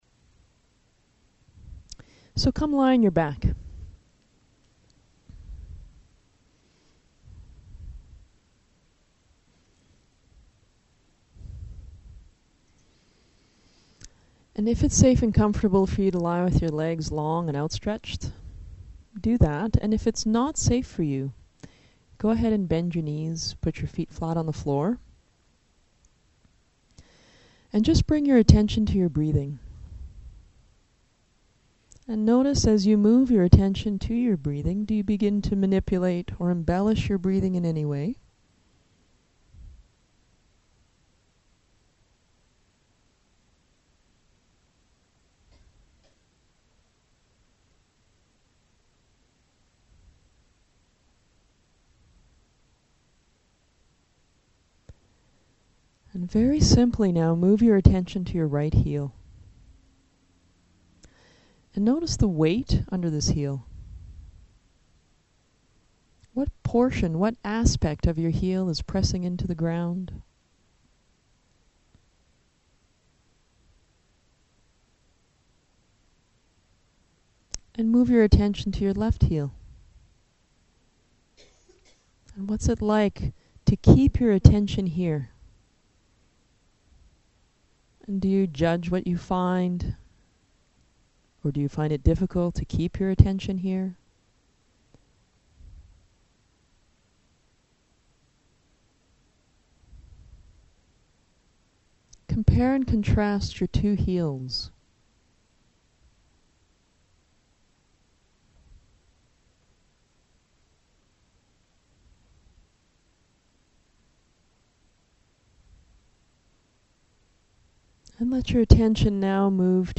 It's a full-length lesson that helps to free up the extra work most of us do in our spine.